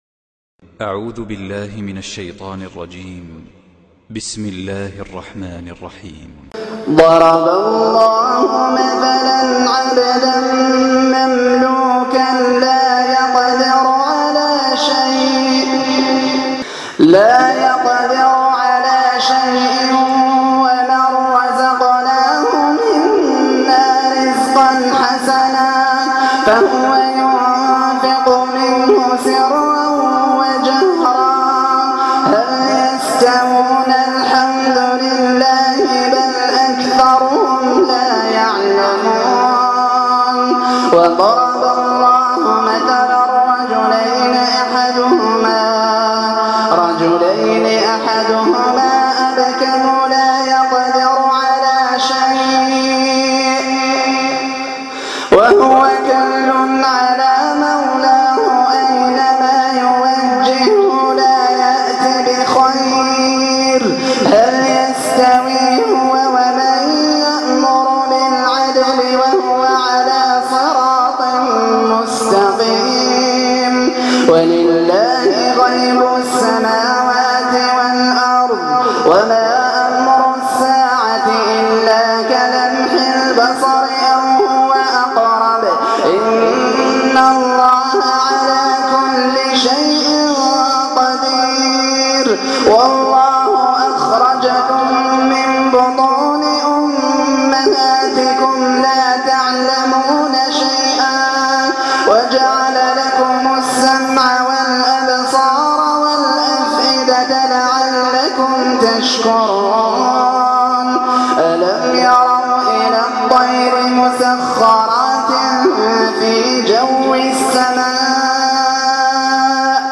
تلاوة خاشعة